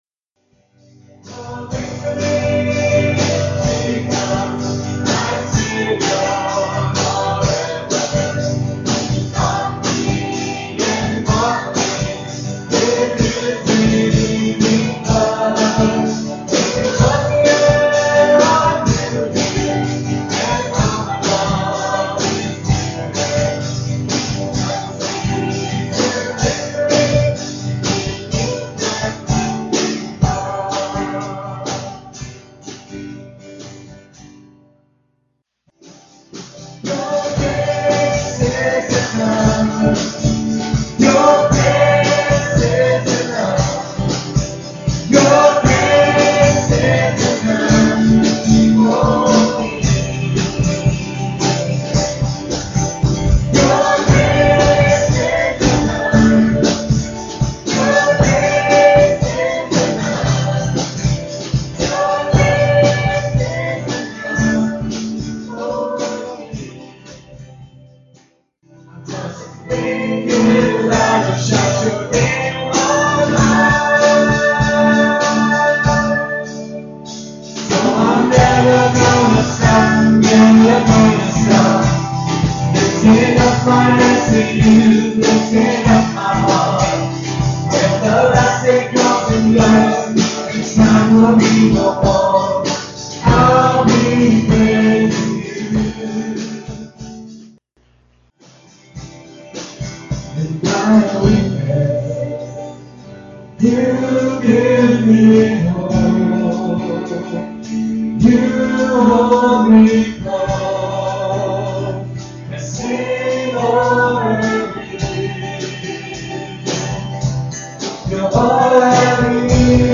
at Ewa Beach Baptist Church